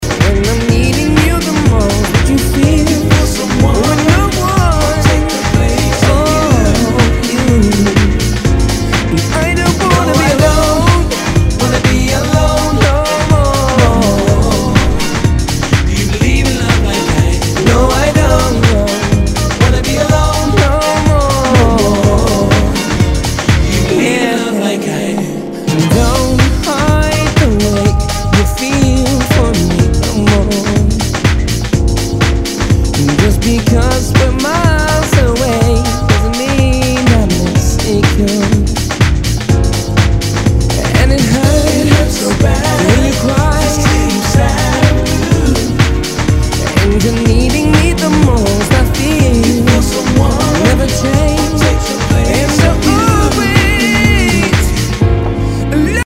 HOUSE/TECHNO/ELECTRO
ナイス！ヴォーカル・ハウス！